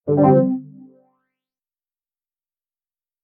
Equip.ogg